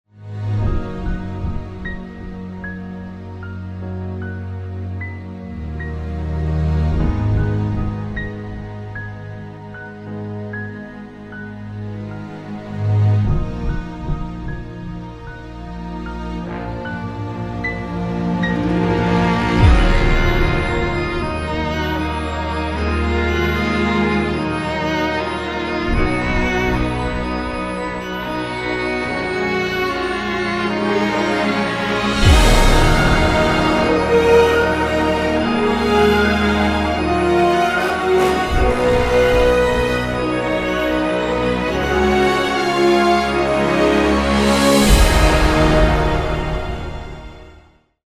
Instrumental
backing track